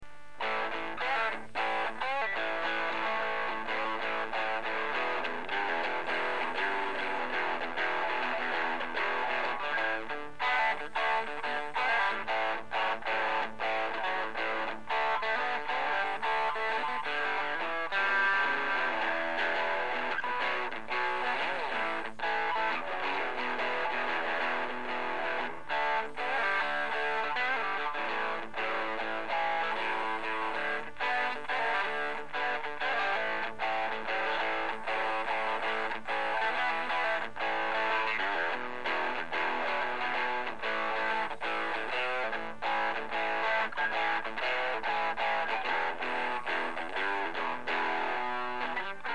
sample lead
scusate per la tecnica agghiacciante.....
lead2.mp3